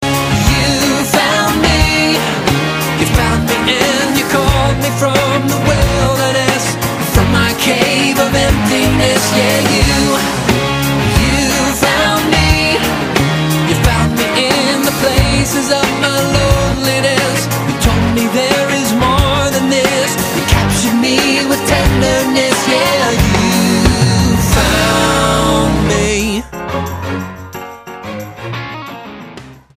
STYLE: Pop
acoustic-driven pop and rock. There's catchy pop songs